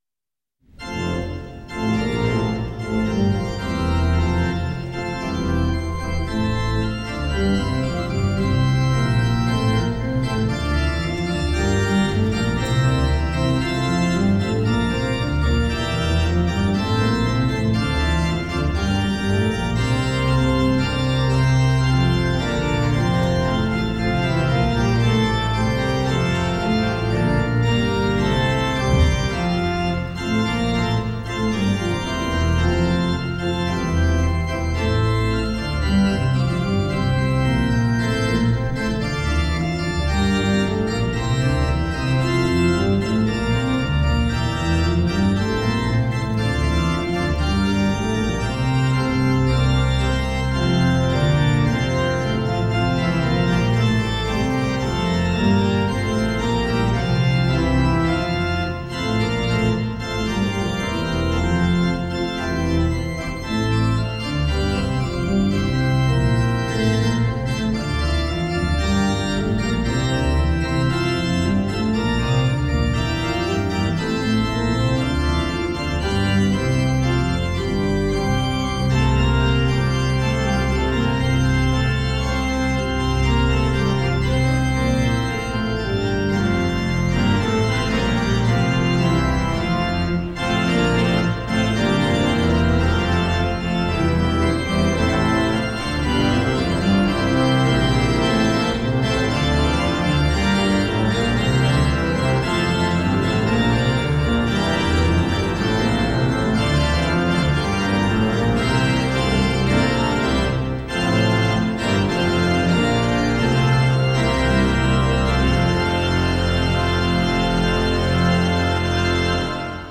Hear the Bible Study from St. Paul's Lutheran Church in Des Peres, MO, from February 1, 2026.
Join the pastors and people of St. Paul’s Lutheran Church in Des Peres, MO, for weekly Bible study on Sunday mornings.